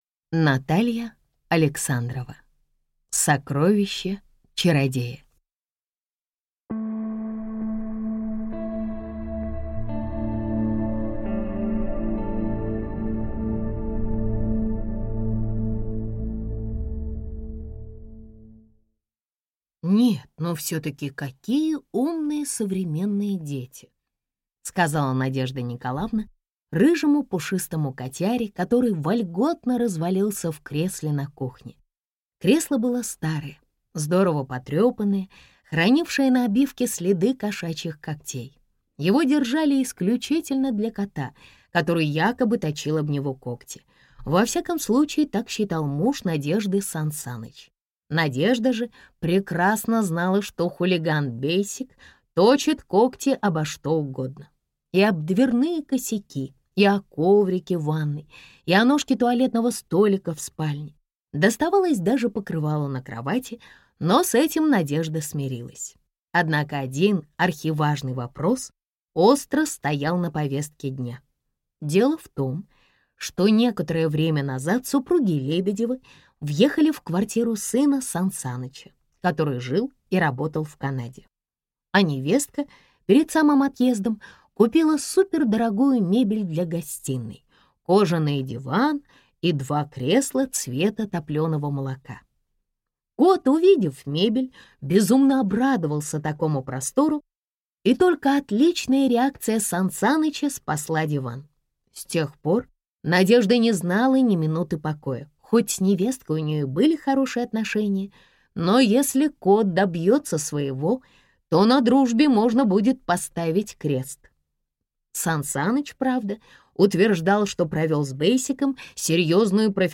Аудиокнига Сокровище чародея | Библиотека аудиокниг